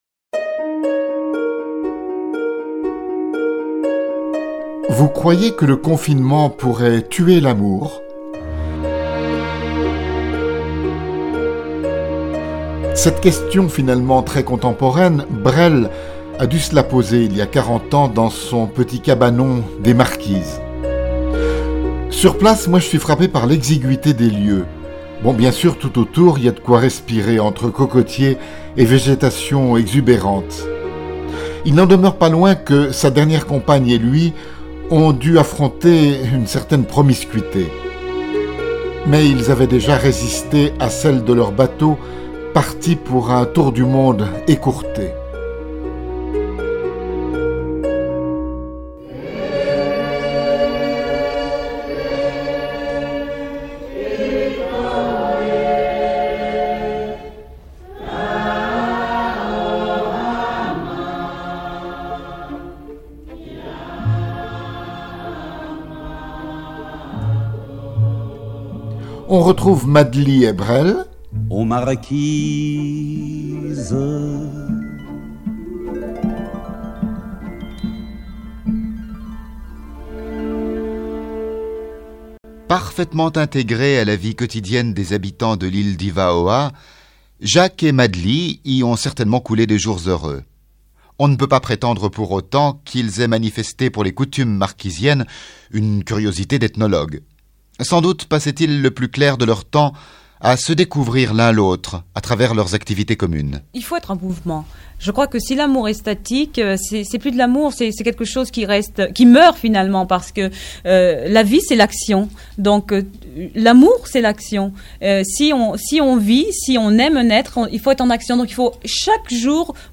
Remerciements aux archives de la RTS.